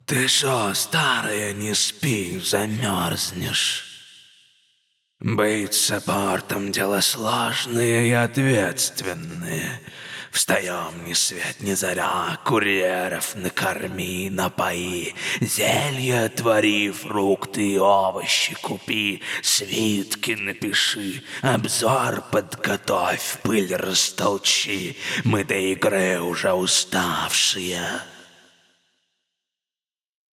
Муж, Пародия(Лич (Dota 2))